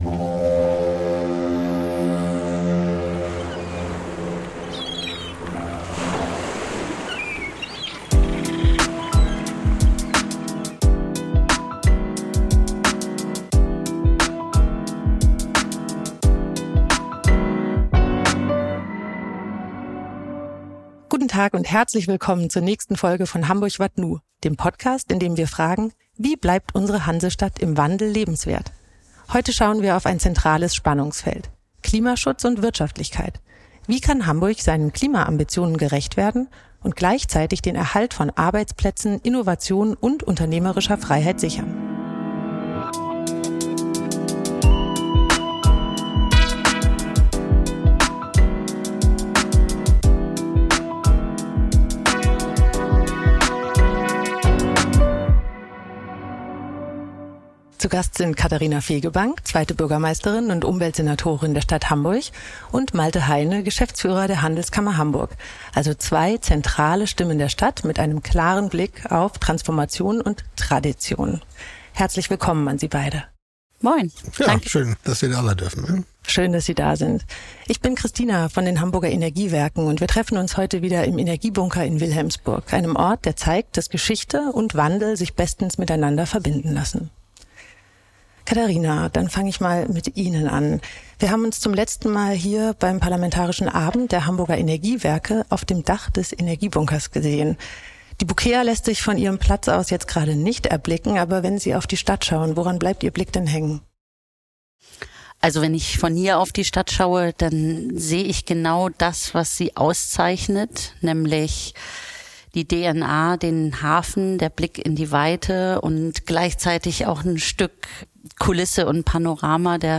Diskutiert werden zentrale Hebel wie Elektrifizierung, Wärmewende, neue Technologien und der Abbau von Bürokratie. Aufgenommen im Energiebunker in Wilhelmsburg – mit Blick auf Hafen, Industrie und eine Stadt im Wandel, in der Klimaschutz und wirtschaftliche Stärke gemeinsam gedacht werden müssen.